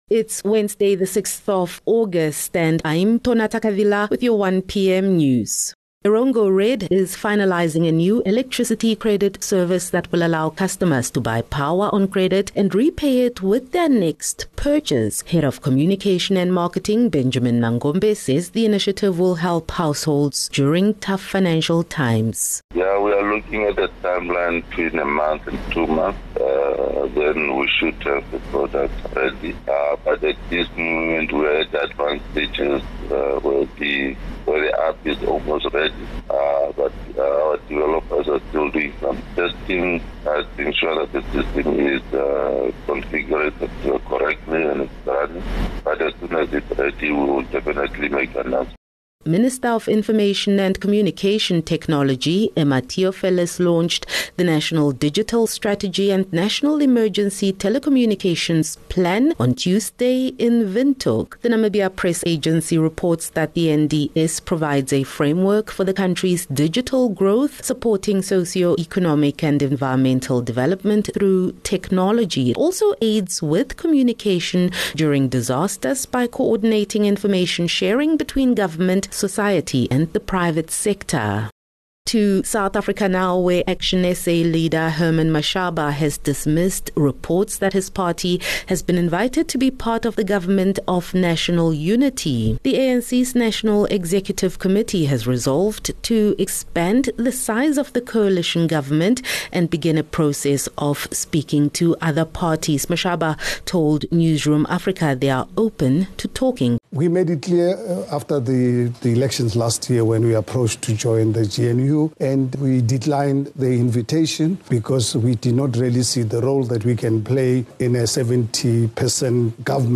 6 Aug 6 August - 1 pm news